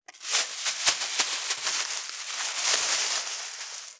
614ゴミ袋,スーパーの袋,袋,買い出しの音,ゴミ出しの音,袋を運ぶ音,
効果音